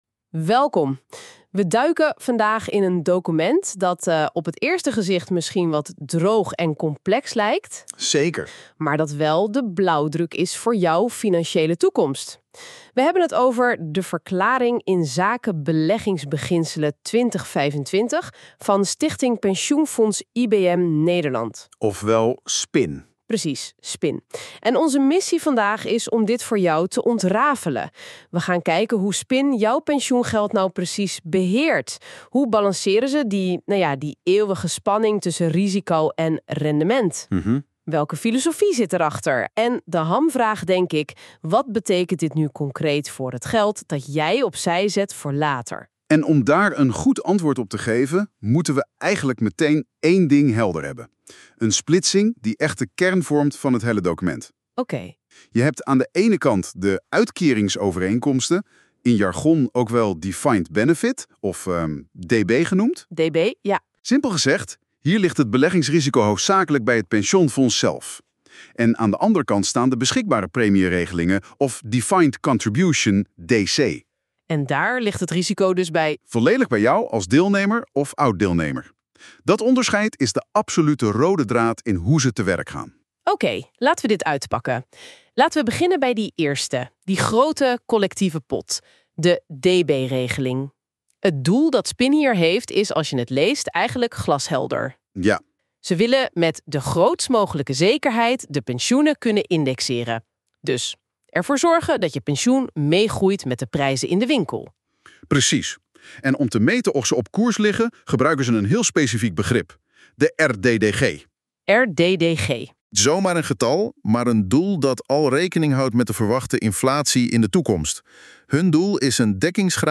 Deze podcast is gemaakt met AI.